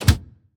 train-door-open-1.ogg